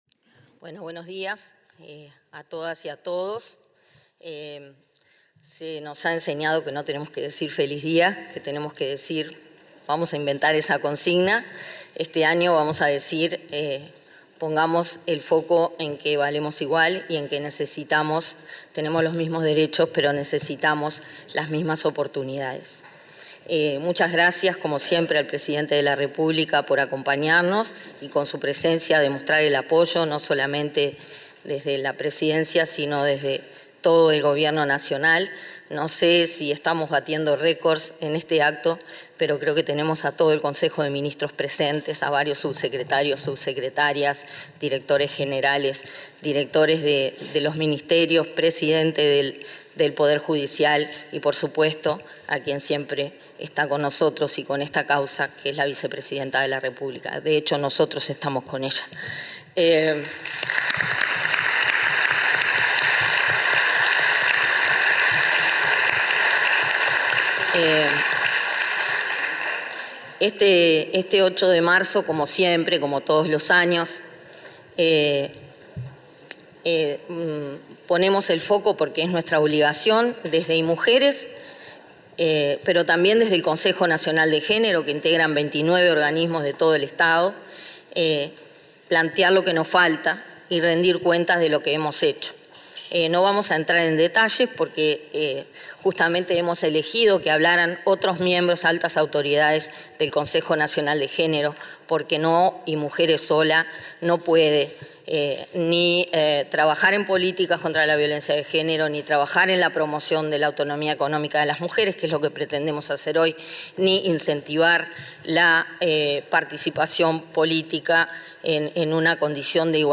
Día Internacional de la Mujer 08/03/2022 Compartir Facebook X Copiar enlace WhatsApp LinkedIn En el Día Internacional de la Mujer, expusieron, en el acto central organizado por el Ministerio de Desarrollo Social (Mides), la directora del Instituto Nacional de las Mujeres (Inmujeres), Mónica Bottero; el ministro de Industria, Omar Paganini; la directora de Desarrollo Social del Mides, Cecilia Sena, y la vicecanciller, Carolina Ache, entre otras autoridades.